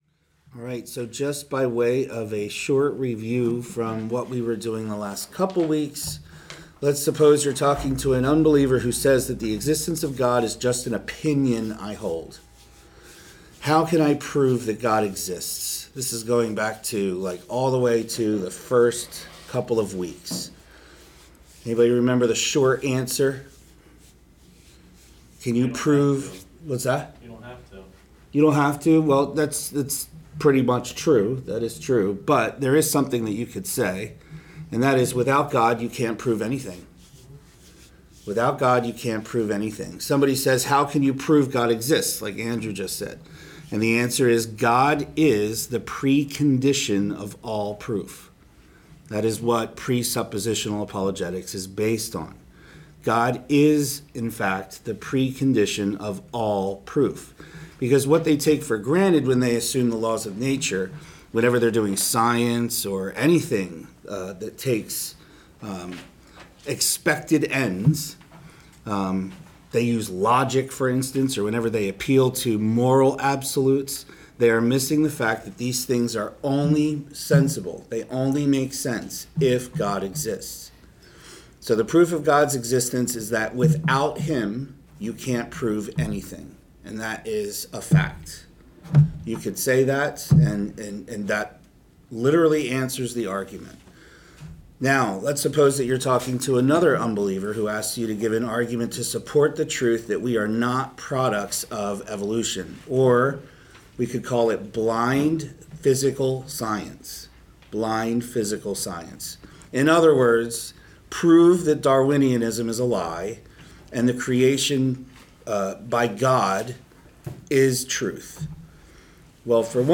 Series: Presuppositional Apologetics, TBC Evening Service
TBC-Sunday-School-5.7.23.m4a